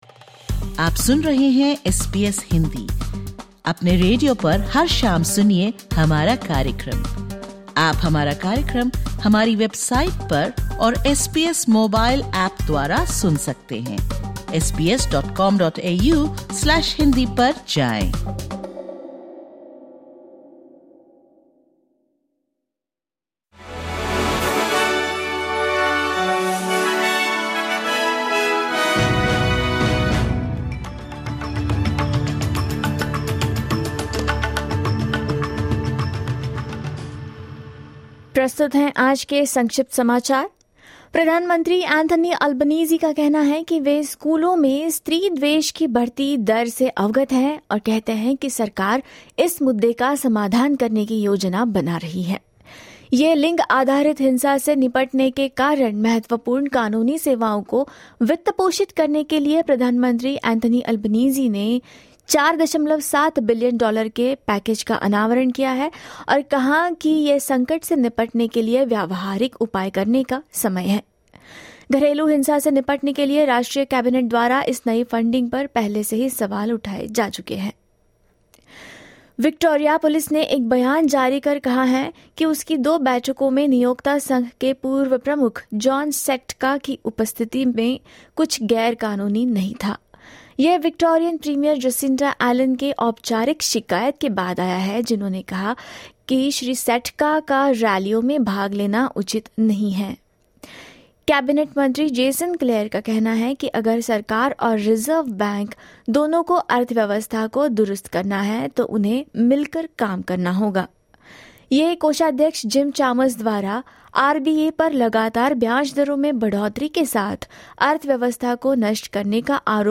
Listen to the top News from Australia in Hindi.